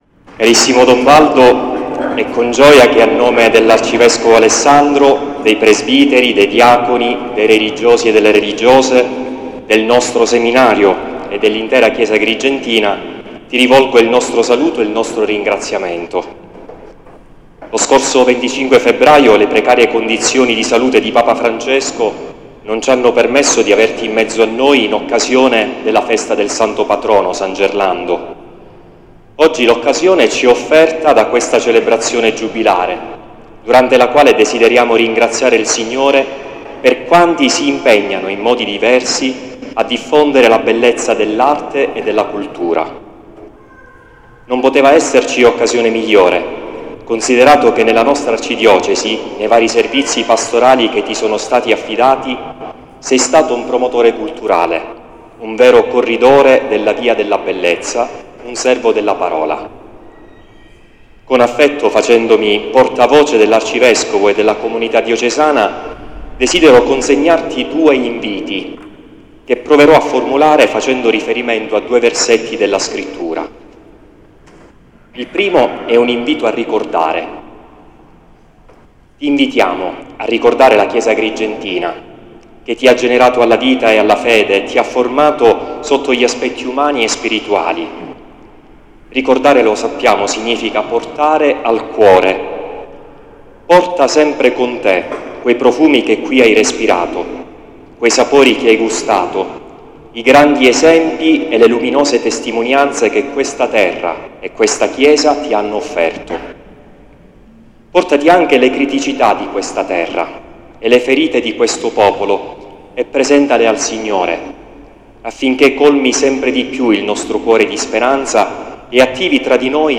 Prima della Messa giubilare si è tenuto un momento introduttivo con i saluti  del dott.